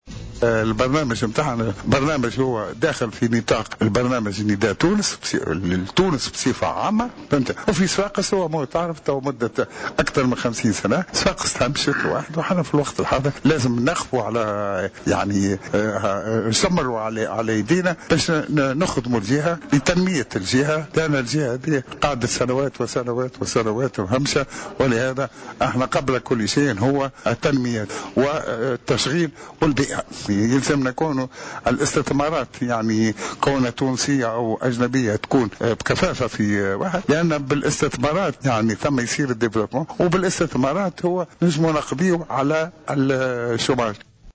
dans une intervention sur les ondes de Jawhara FM